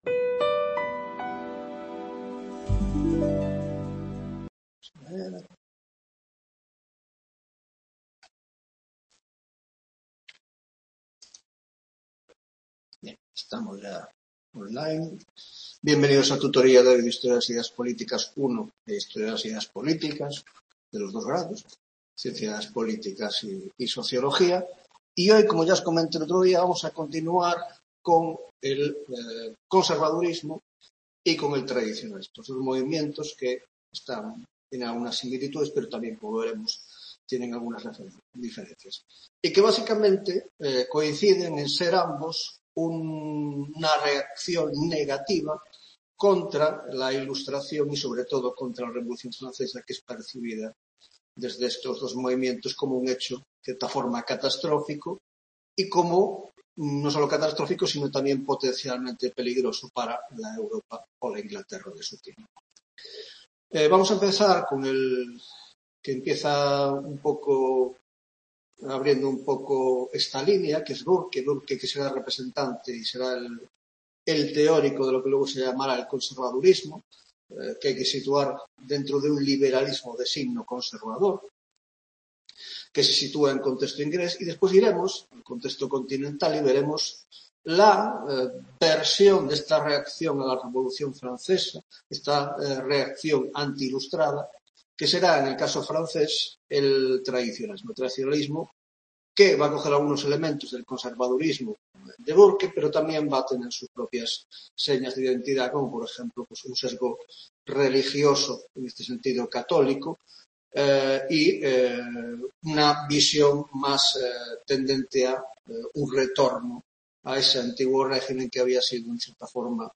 6ª Tutoría de Historia de las Ideas Políticas II (Grado de Ciencias Políticas ) - Conservadurismo y Tradicionalismo (1ª parte): 1) Introducción: 1.1) Conservadurismo y tradicionalismo como reacciones a la Revolución Francesa